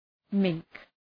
Shkrimi fonetik {mıŋk}